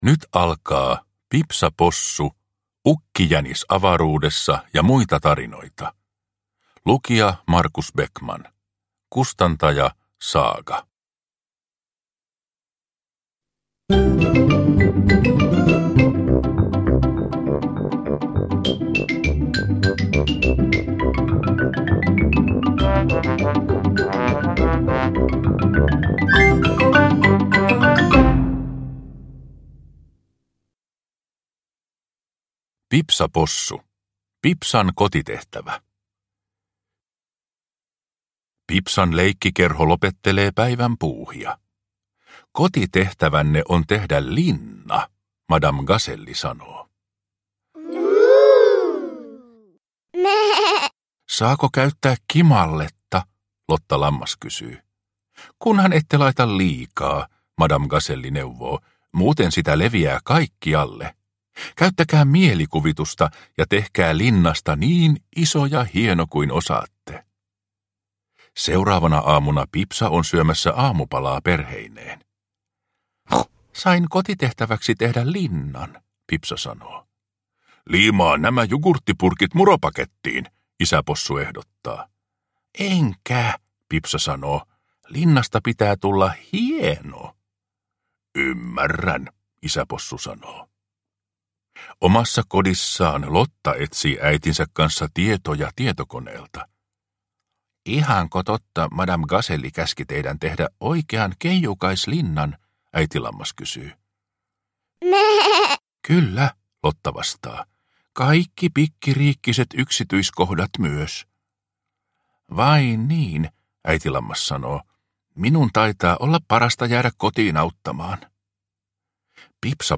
Pipsa Possu - Ukkijänis avaruudessa ja muita tarinoita (ljudbok) av Neville Astley